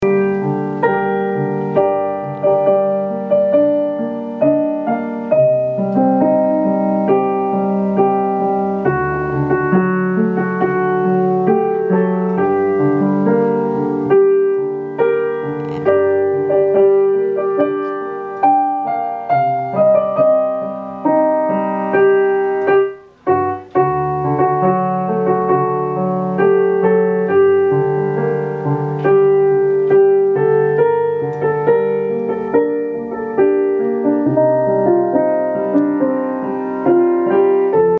פסנתר